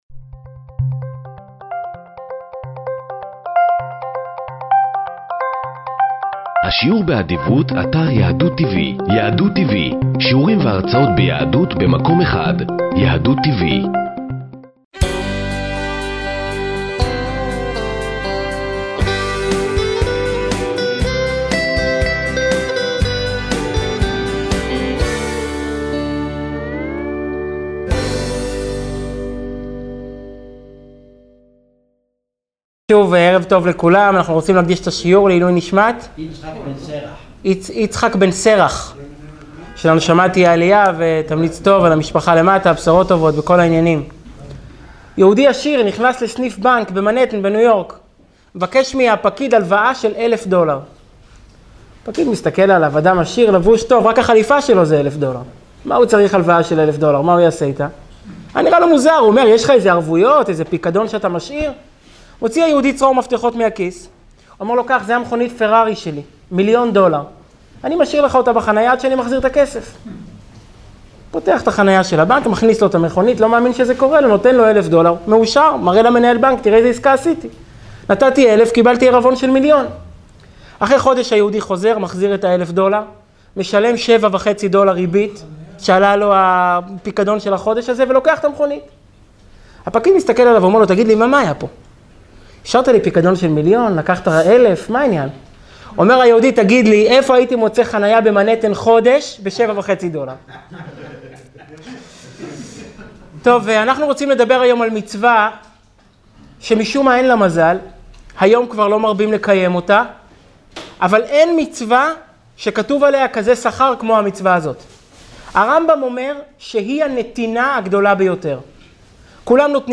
שיעור מרתק לפרשת משפטים
שנמסר בביהכנ"ס חב"ד בראשל"צ